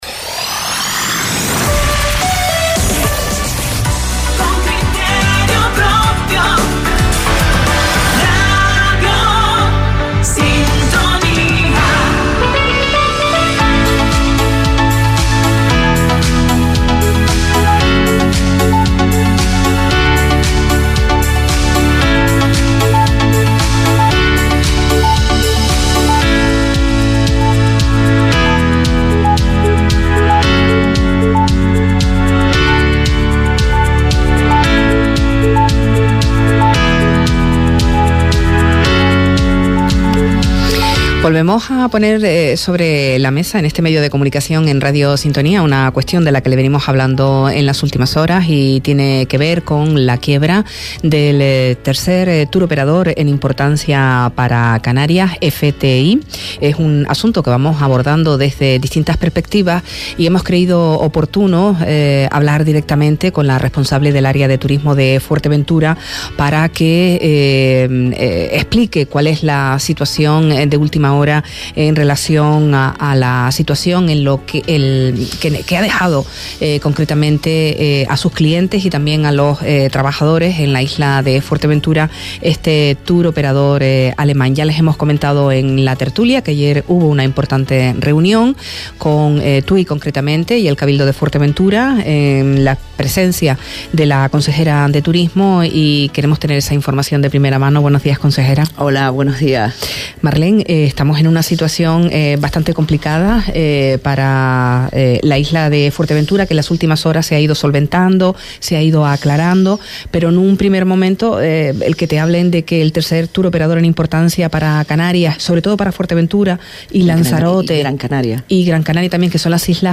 Marlen Figueroa, consejera de Turismo del Cabildo mandó hoy desde los micrófonos de Radio Sintonía un mensaje de tranquilidad a todo los agentes y personal de los centros hoteleros en Fuerteventura gestionados por el turoperador FTI que, como se recordará, presentó quiebra esta semana.
Entrevistas